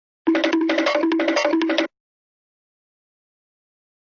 Звуки поскальзывания
Под ногами скользкая банановая кожура